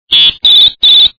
三声电子口哨报警芯片是一颗价格便宜，性价比极高的OTP芯片，共有3个I/O口，存储3段口哨报警音效，音质效果好，音量大，声音尖锐，穿透力强。
电子口哨三声报警下载1,仅供参考。
声音内容：电子口哨音效，上电长响
AC3KS03whistle.mp3